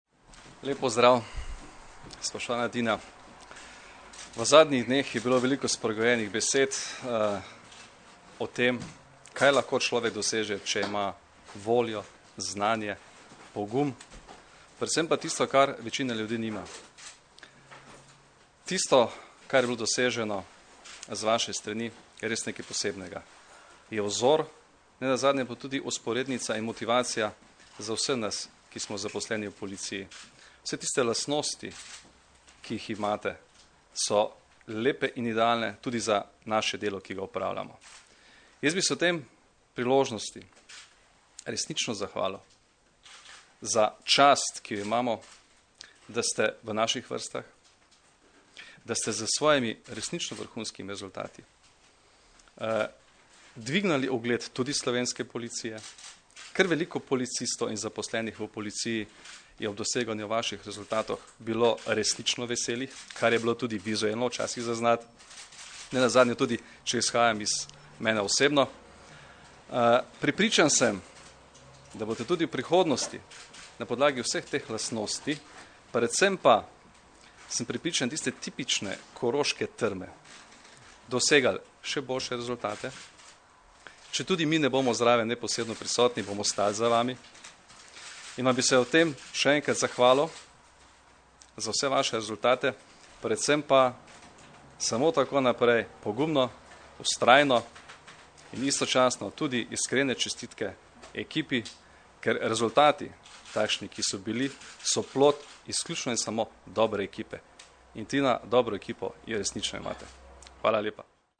Zvočni posnetek izjave Janka Gorška (mp3)